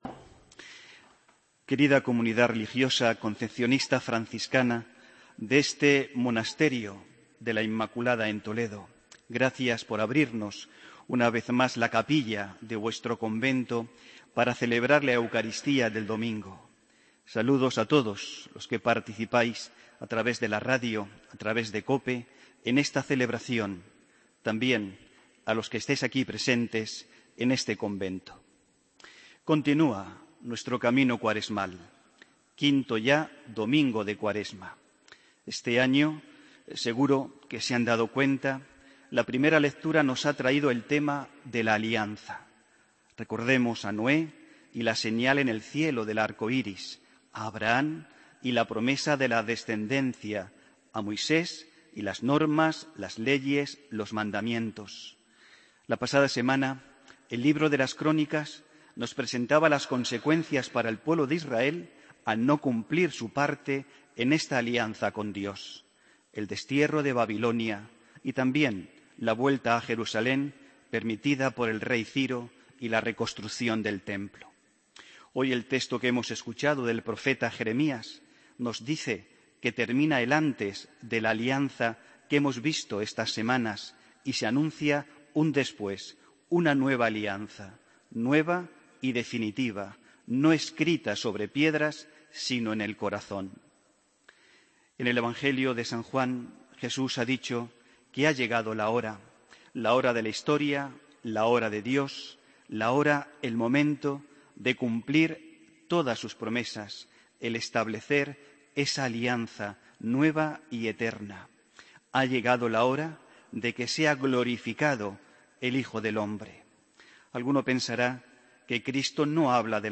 Homilía del domingo 22 de marzo de 2015